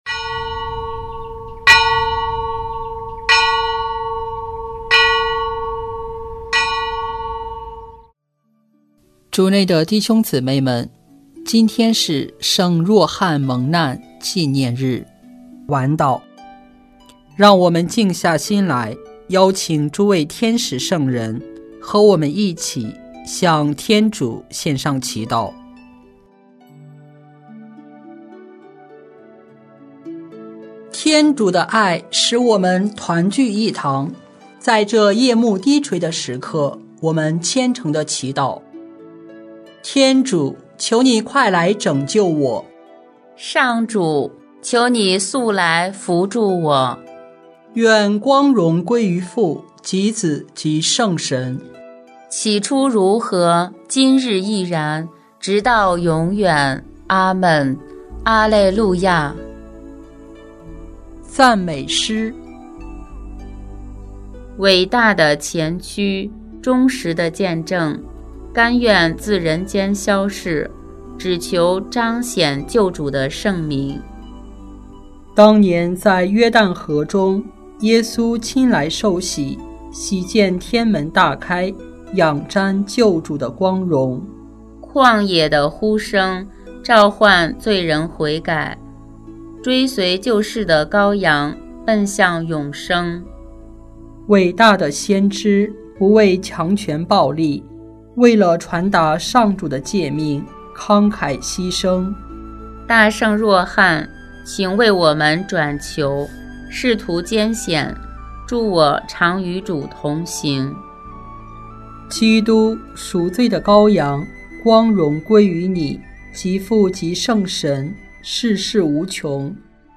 圣咏吟唱 圣咏 114 对经一： 主说：你们不要怕他们，因为我与你们同在。